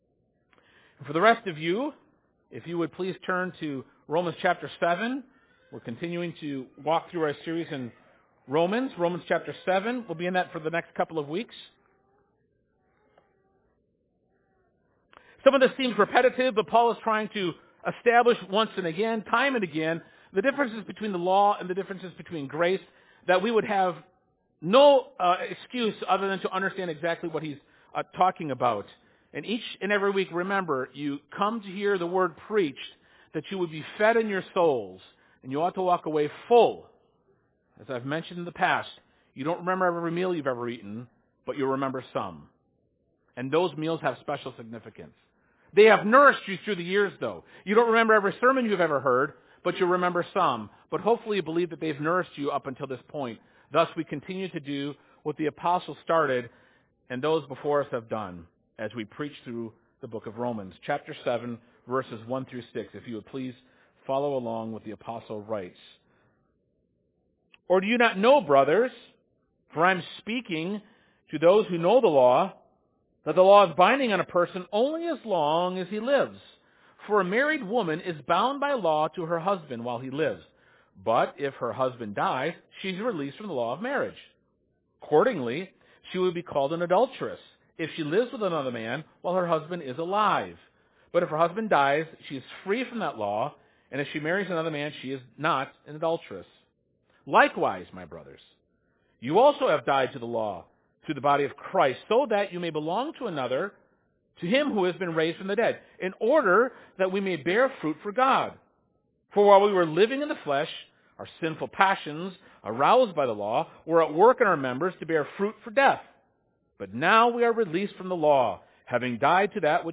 Bible Text: Romans 7:1-6 | Preacher